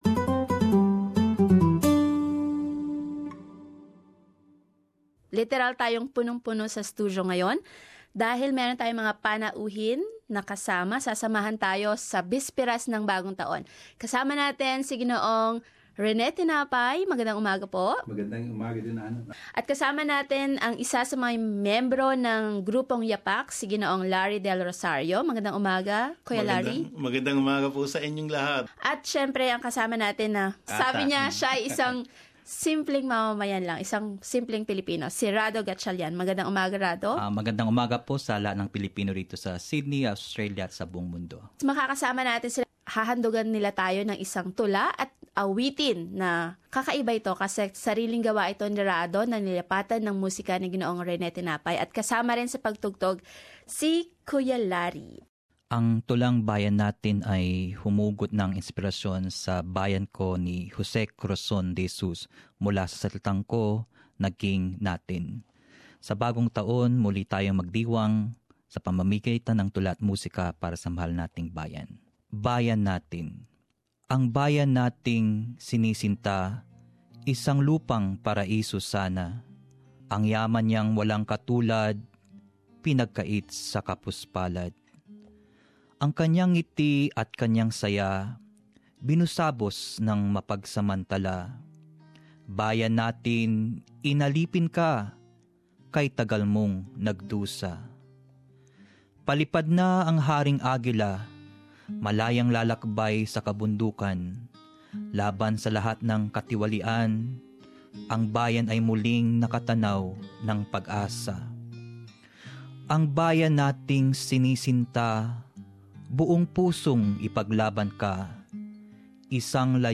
in the SBS studio in Sydney